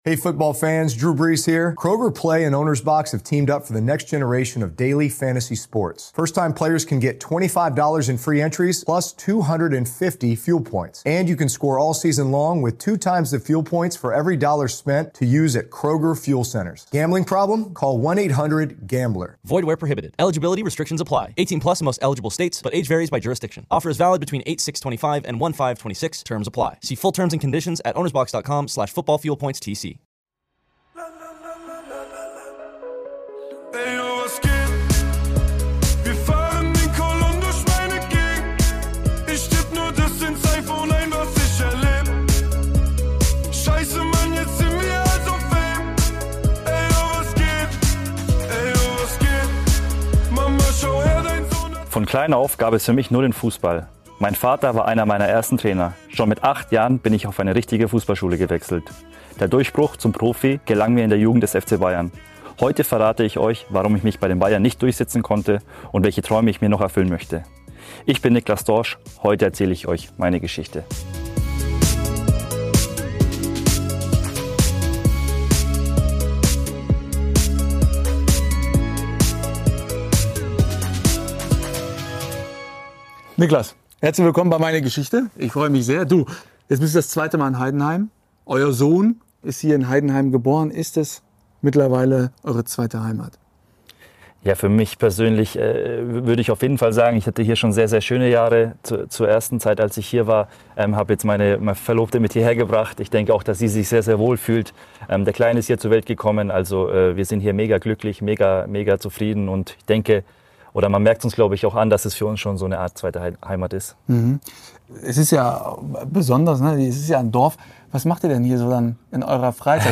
Dabei stehen persönliche Geschichten abseits des Spielfelds im Vordergrund. Wir blicken im Gespräch hinter die Kulissen der Sportler oder der Sport-Persönlichkeiten.